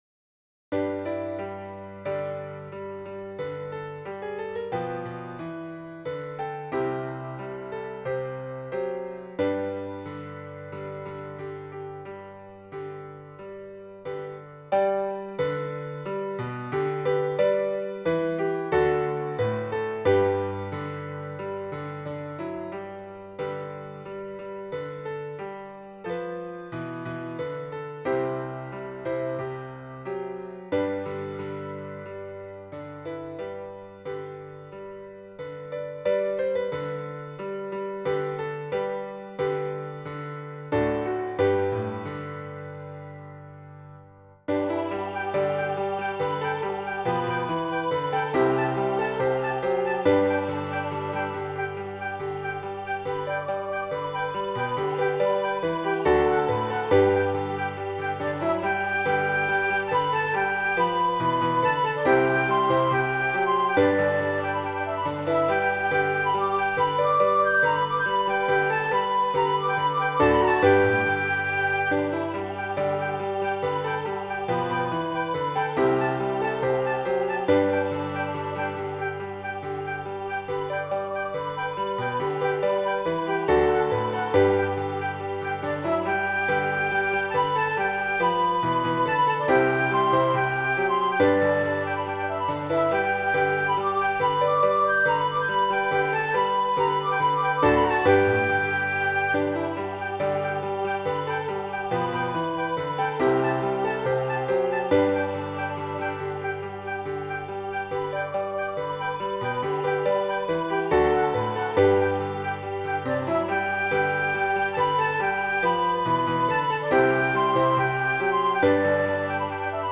This arrangement is for flute, voice and piano.